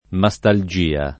[ ma S tal J& a ]